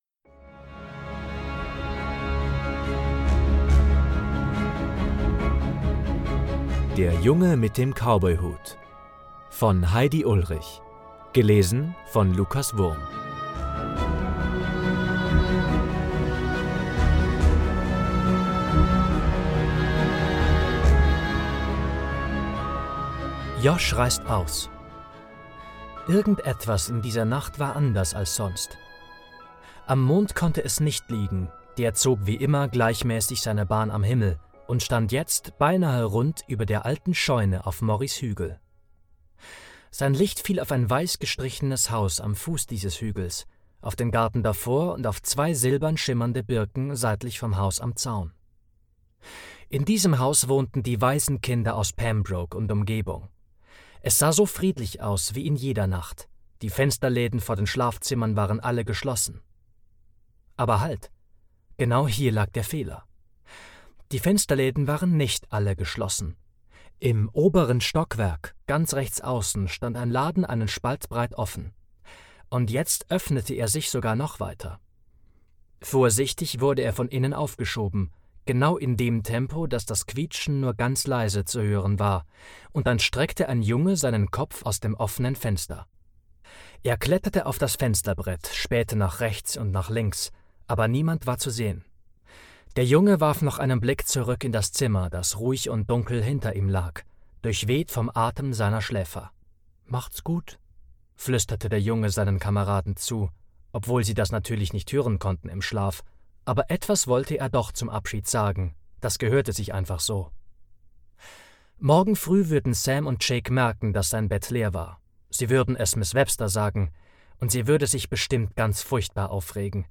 Ein Hörbuch nach dem gleichnamigen Buch.